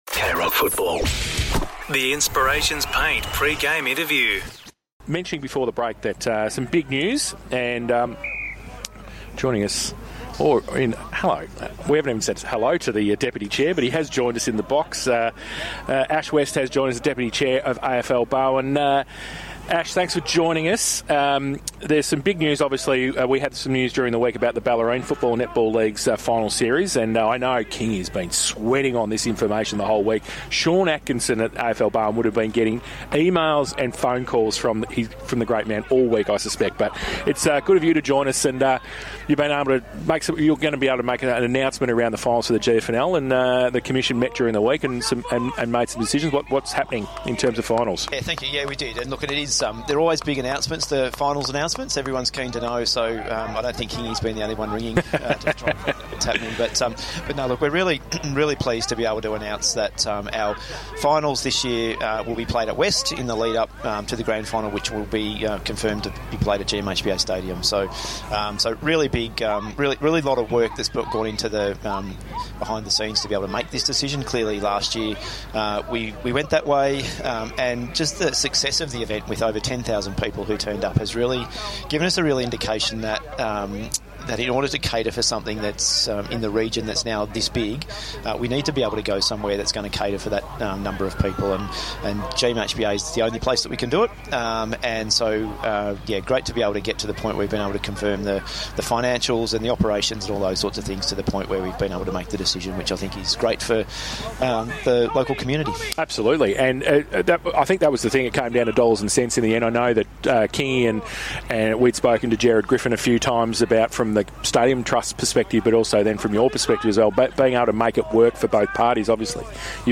2025 - GFNL - Round 13 - South Barwon vs. Bell Park: Pre-match interview